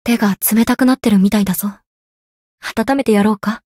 灵魂潮汐-南宫凛-圣诞节（摸头语音）.ogg